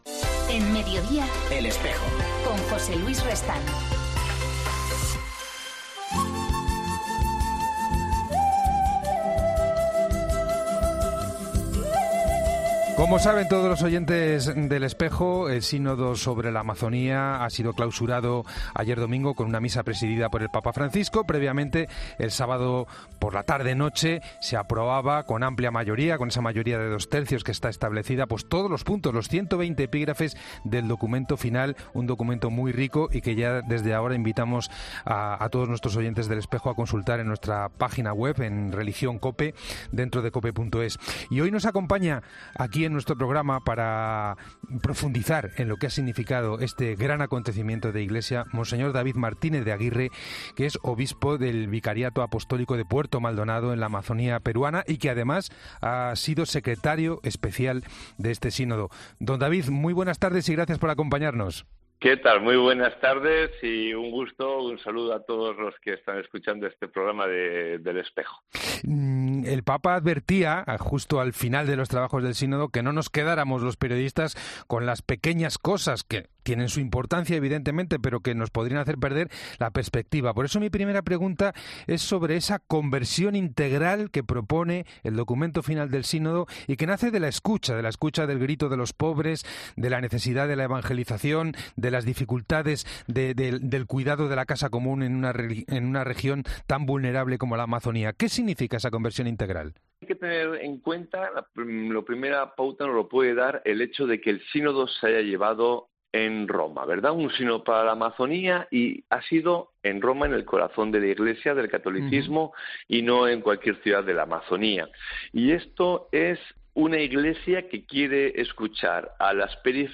En El Espejo hacemos hoy balance del Sínodo sobre la Amazonía con Mons. David Martínez de Aguirre, Obispo del Vicariato Apostólico de Puerto Maldonado (Perú), que ha sido uno de los secretarios especiales nombrados por el Papa para la asamblea sinodal.